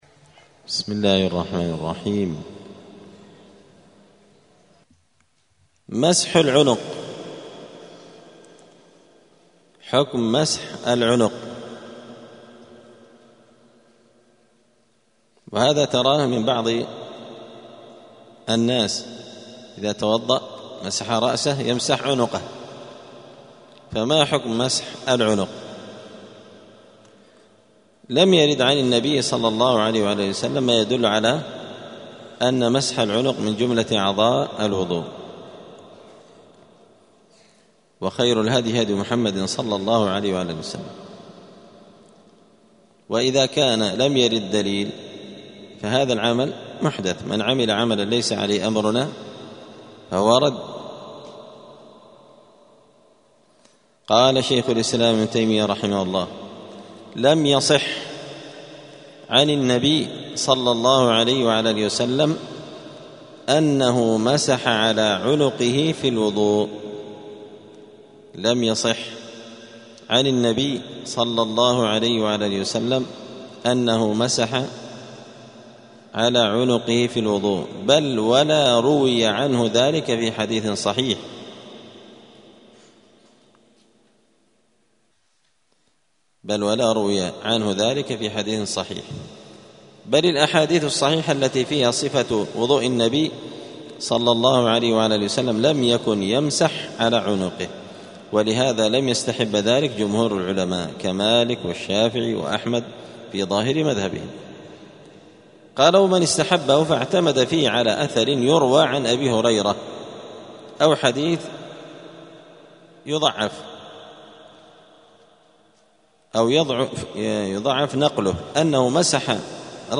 دار الحديث السلفية بمسجد الفرقان قشن المهرة اليمن
*الدرس الثالث والثلاثون [33] {باب صفة الوضوء حكم الترتيب في الوضوء…}*